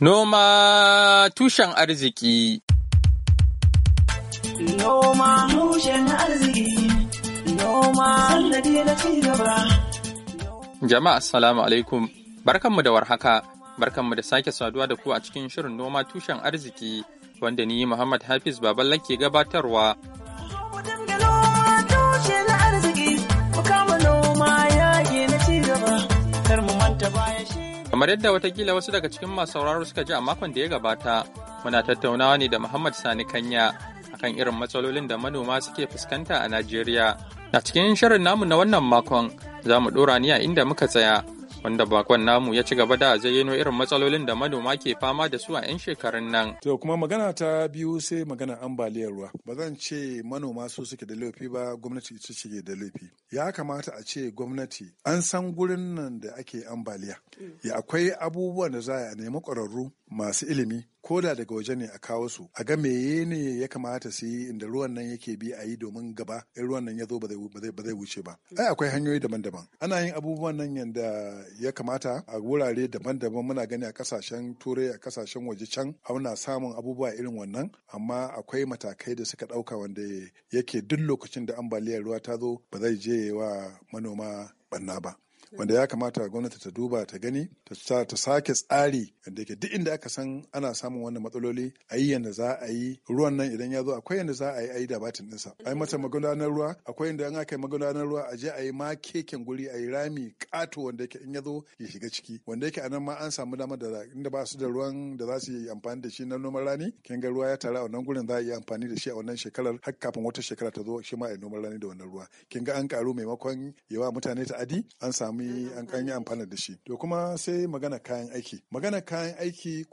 NOMA TUSHEN ARZIKI: Hira Da Manomi Kan Irin Kalubalen Da suke Fuskanta a Najeriya - 6'50